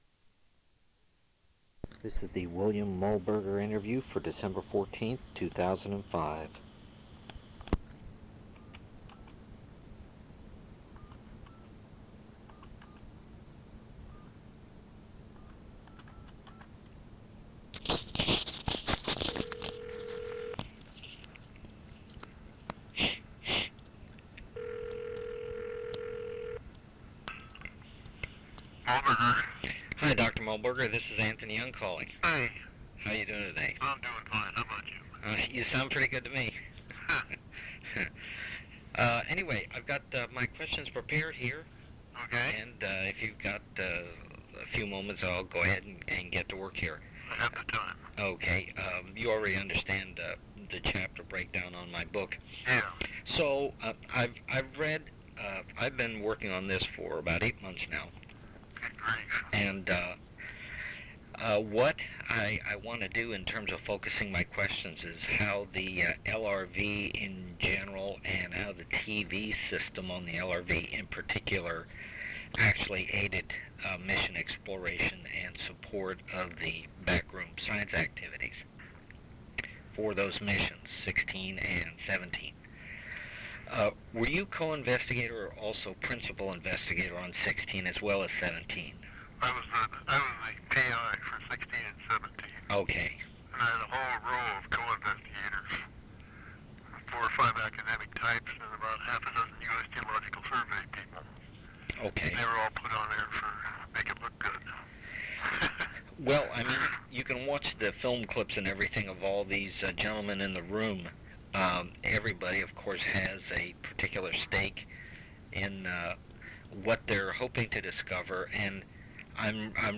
Interviews
Oral History